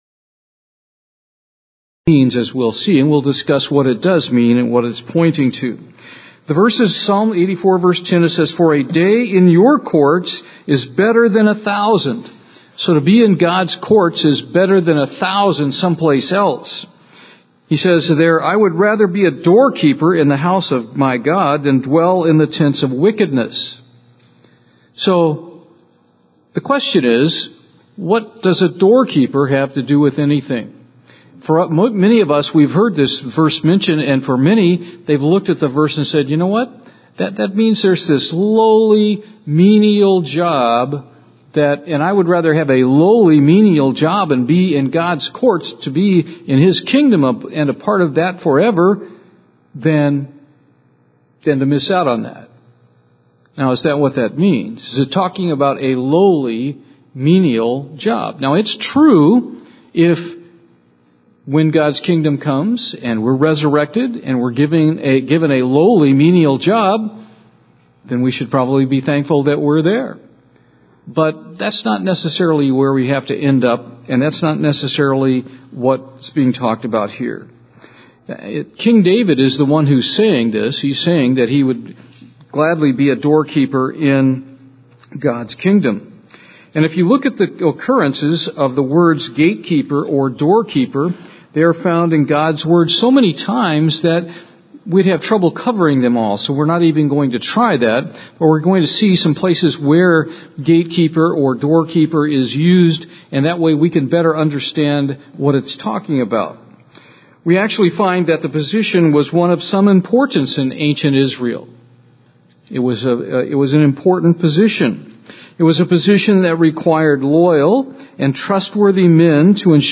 Many take this to mean that a doorkeeper is the lowest position in the kingdom. In this sermon
Given in Houston, TX